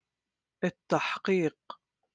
Jordanian